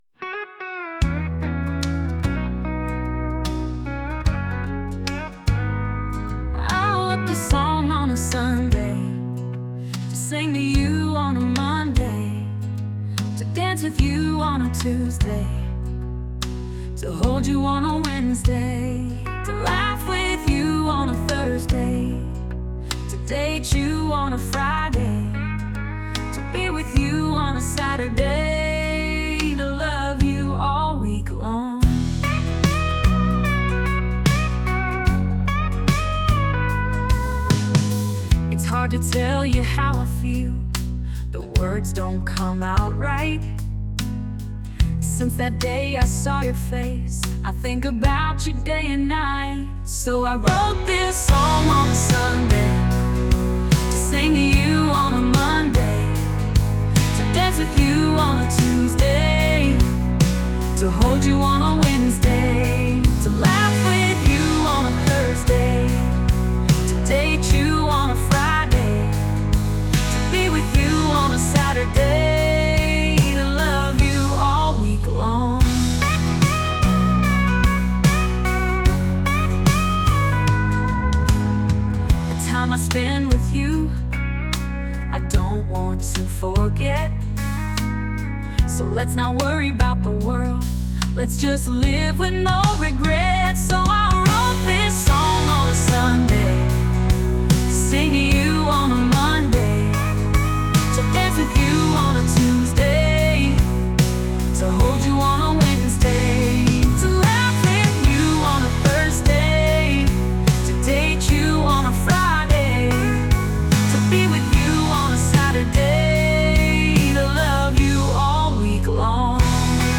country music song